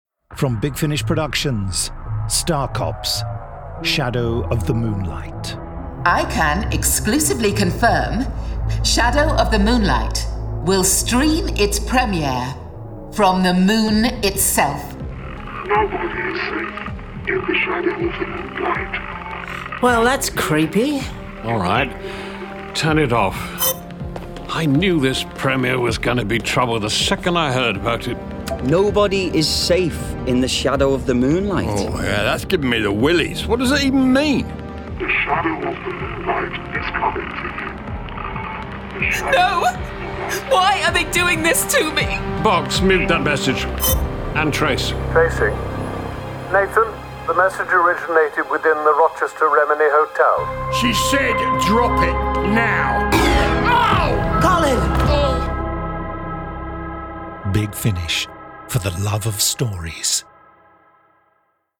Star Cops 5.2. Star Cops: Conflict: Shadow of the Moonlight Released February 2026 Written by Mark Wright Starring David Calder Trevor Cooper This release contains adult material and may not be suitable for younger listeners. From US $13.52 Download US $13.52 Buy Save money with a bundle Login to wishlist 5 Listeners recommend this Share Tweet Listen to the trailer Download the trailer